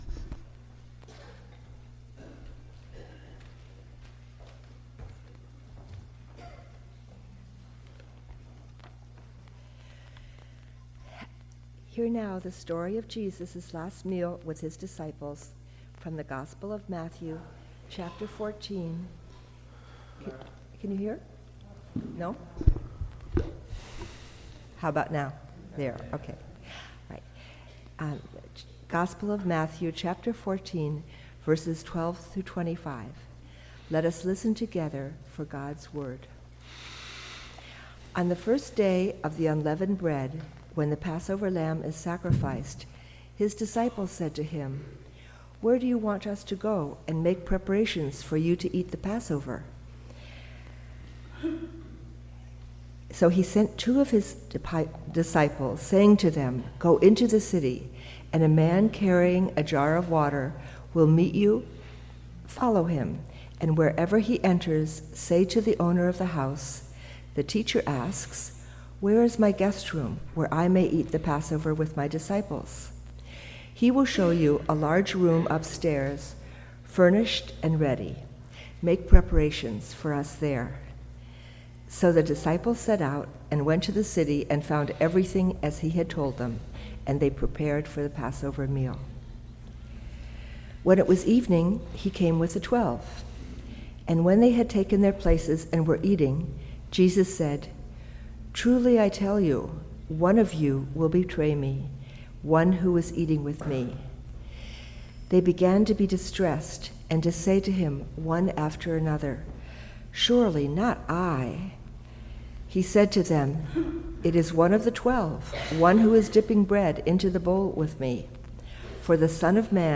Date: October 6th, 2019 (Pentecost 17 – World Communion Sunday)
Message Delivered at: Charlotte Congregational Church (UCC)